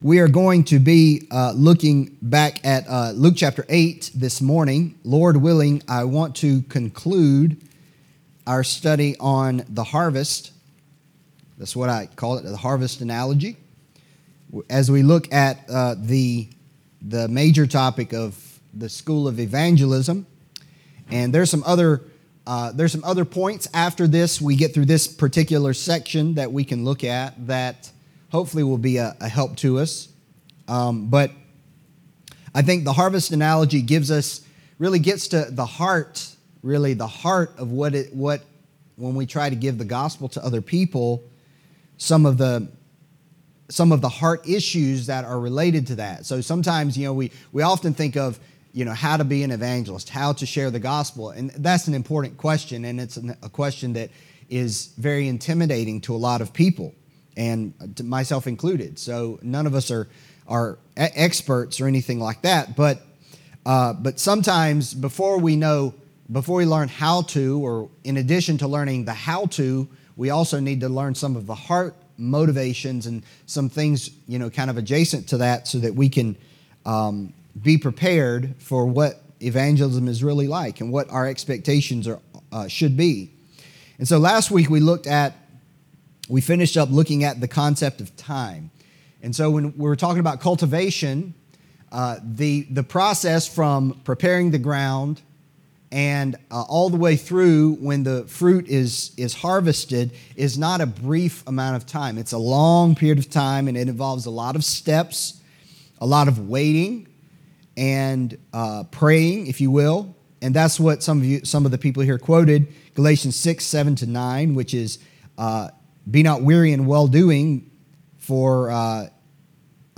Adult Sunday School: School of Evangelism &middot